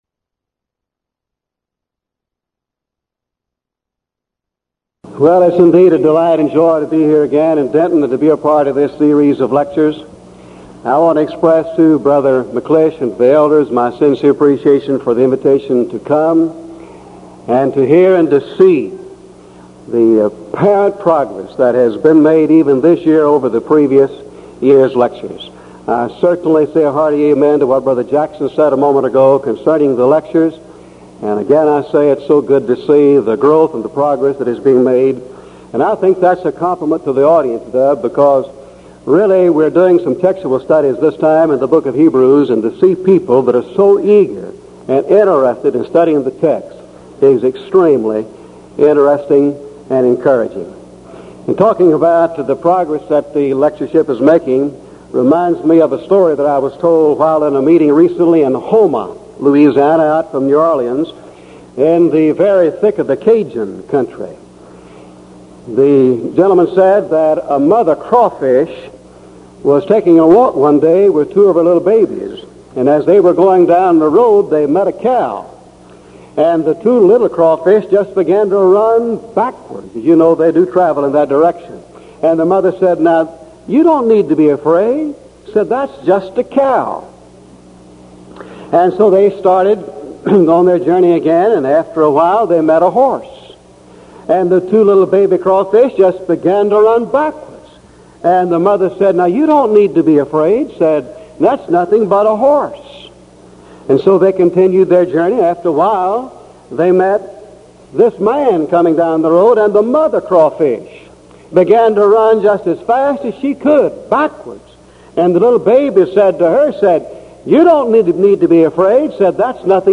Event: 1983 Denton Lectures Theme/Title: Studies in Hebrews
this lecture